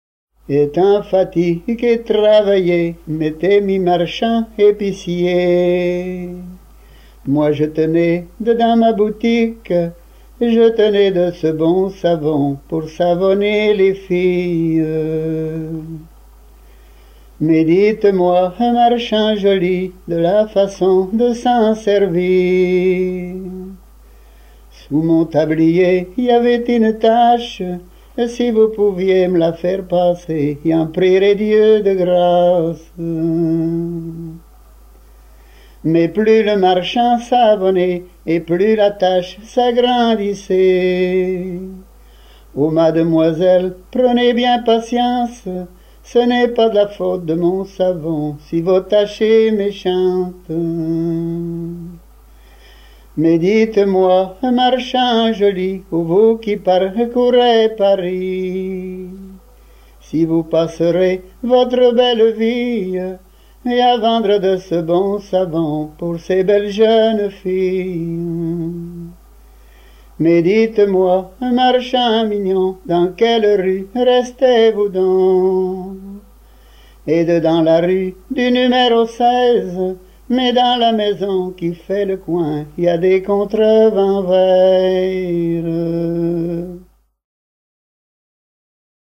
circonstance : fiançaille, noce ;
Genre strophique
Pièce musicale éditée